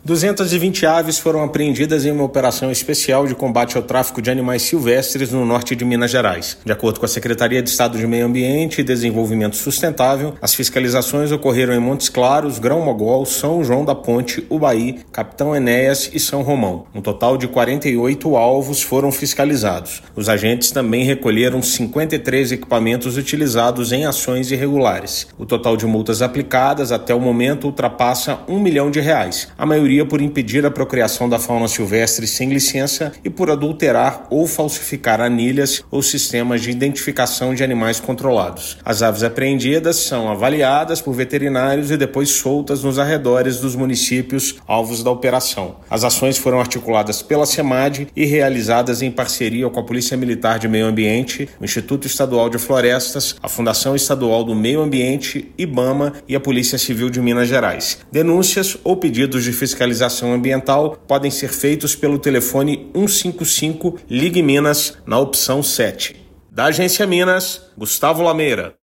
Aves foram avaliadas por veterinários e algumas já foram soltas nas proximidades dos municípios alvos da operação. Ouça matéria de rádio.